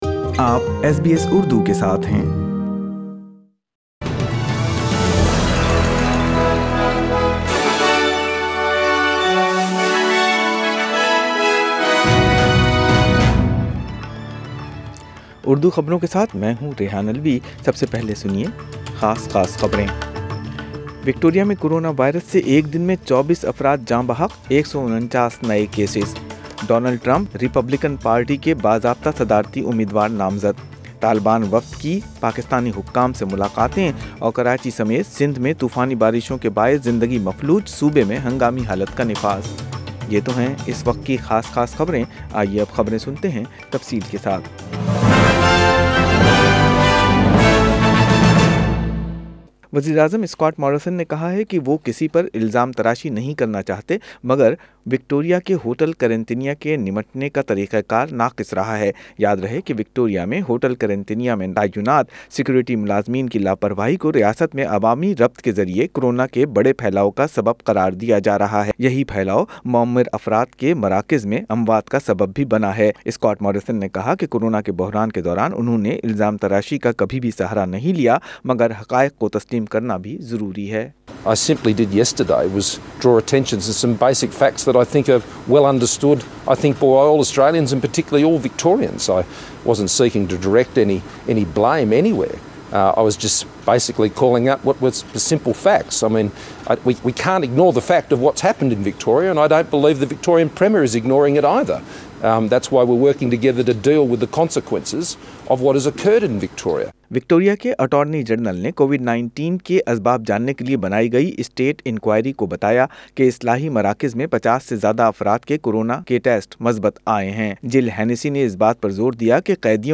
اردو خبریں بدھ 26 اگست 2020